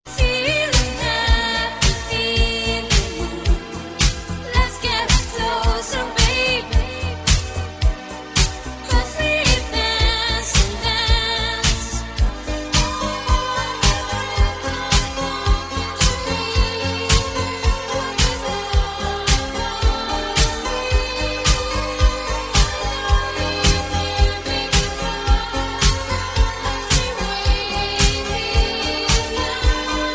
Quelle voix affreuse!